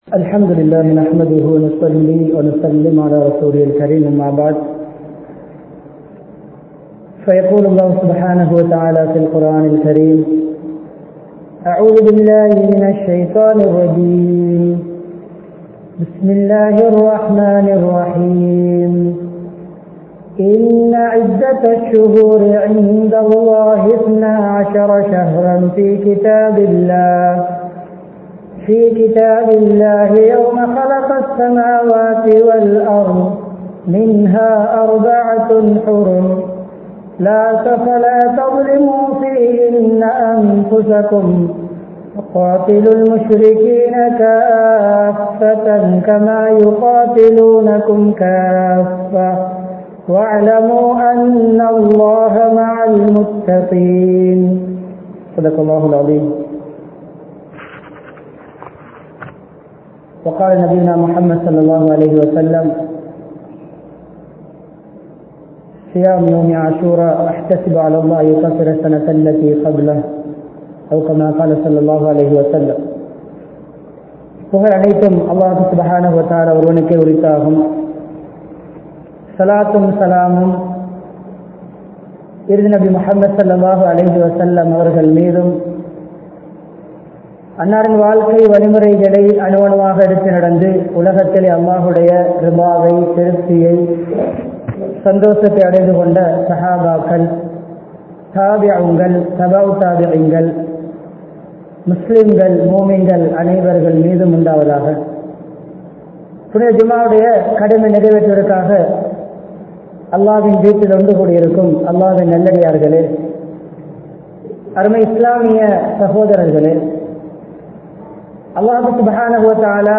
Muharramum Vaalkaiel Maattramum (முஹர்ரமும் வாழ்க்கையில் மாற்றமும்) | Audio Bayans | All Ceylon Muslim Youth Community | Addalaichenai
Town Jumua Masjidh